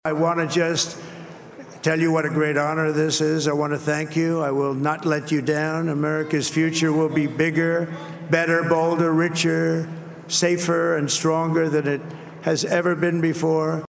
WZTH-TRUMP-ACCEPTANCE.mp3